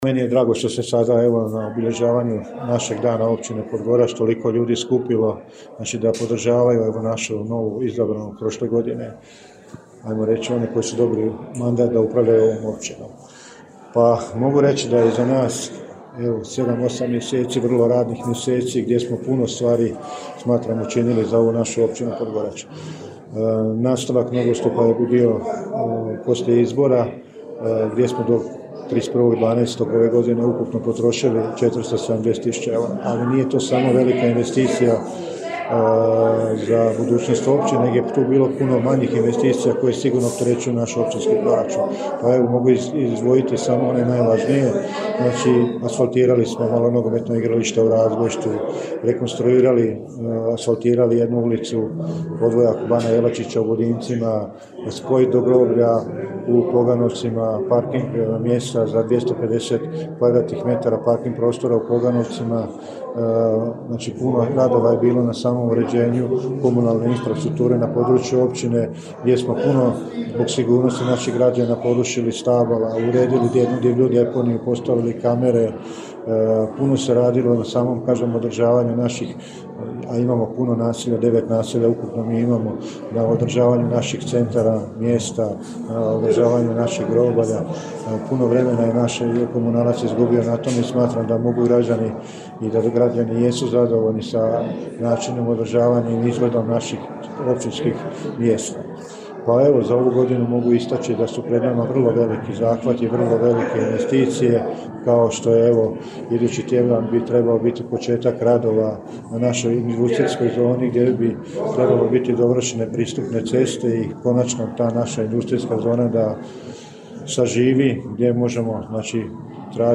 Svečana sjednica Općinskog vijeća
U nastavku današnjeg programa održana je svečana sjednica Općinskog vijeća.